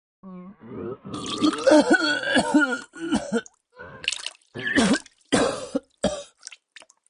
Звуки рвоты, тошноты
Звук рвоты с кашлем